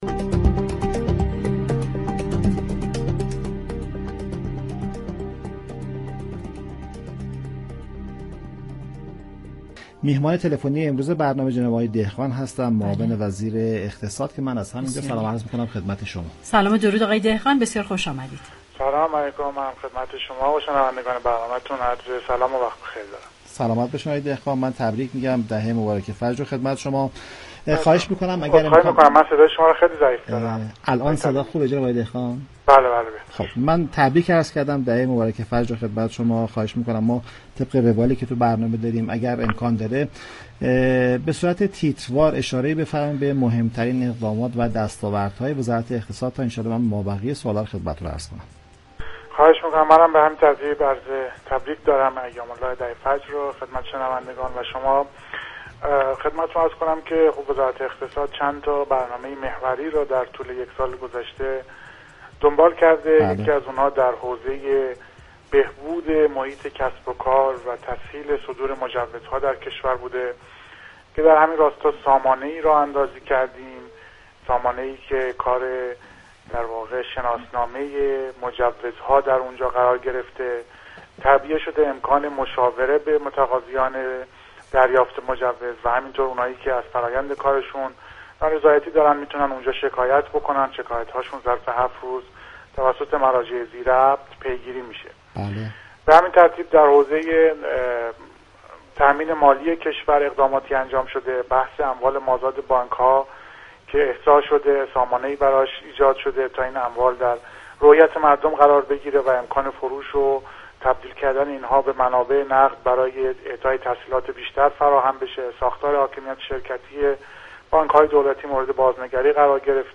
معاون وزیر اقتصاد نوزدهم بهمن ماه از رادیو تهران پایتخت‌نشینان را در جریان فعالیت‌های این وزارتخانه برای تسهیل امور اقتصادی كشور قرار داد.